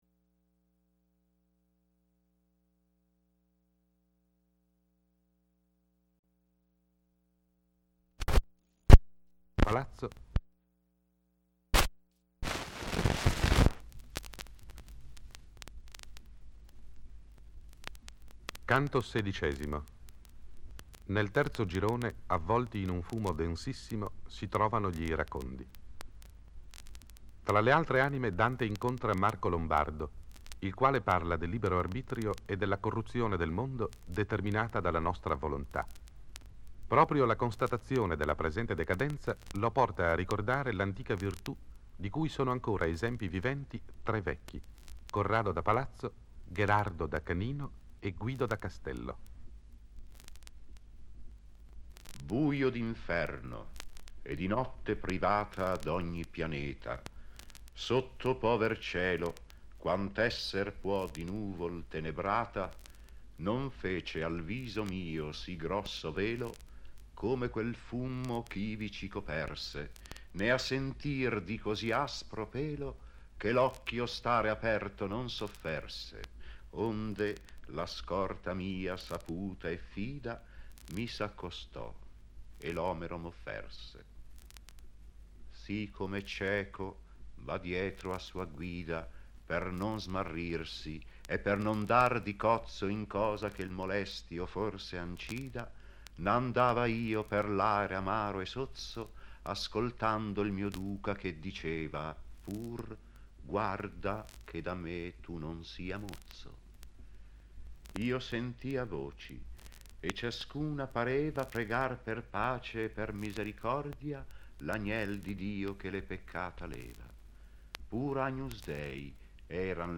Lettore, Tino Carrara